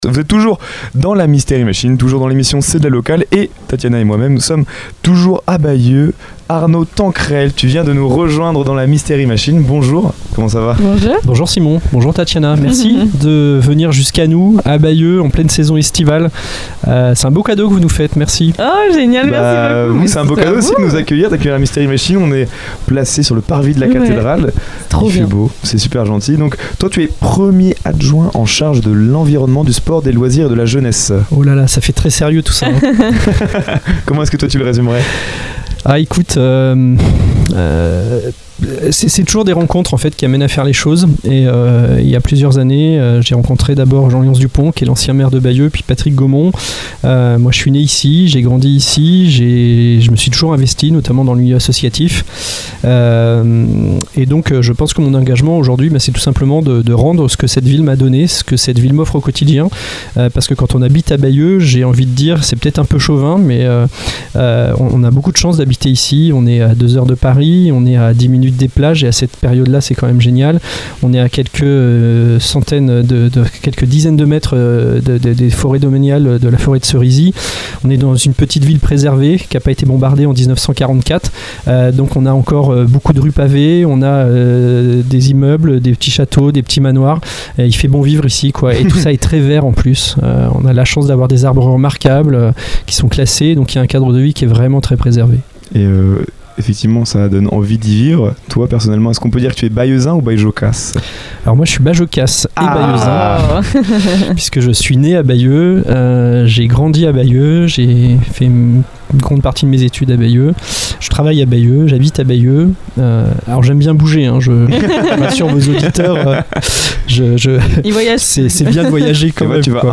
Dans cet épisode du Mystery Machine Summer Tour, nous faisons escale à Bayeux pour rencontrer Arnaud Tanquerel, premier adjoint au maire de Bayeux. Au micro, il nous présente les grands projets portés par la municipalité, le dynamisme de la ville et les actions mises en place pour renforcer son attractivité.
Une interview informative et conviviale qui permet de mieux comprendre les ambitions et l’évolution d’une ville emblématique de Normandie, dans l’esprit du Mystery Machine Summer Tour.